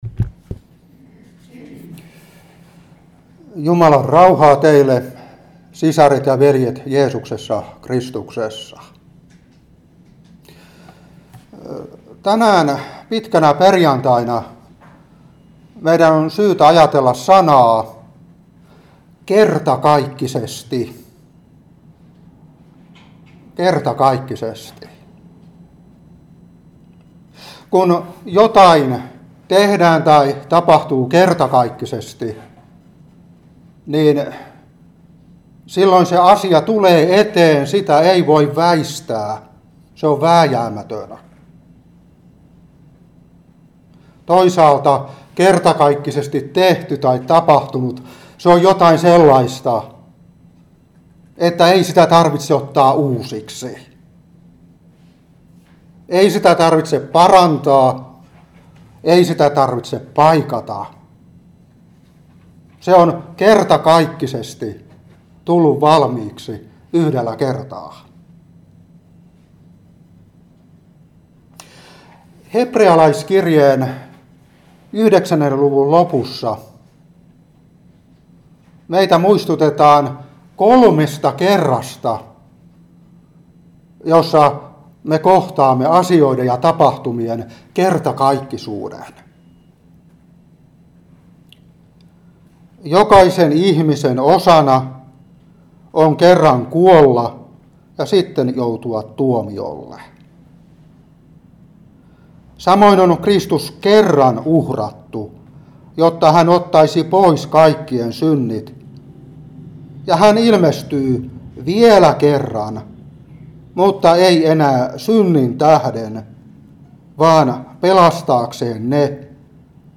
Seurapuhe 2021-4.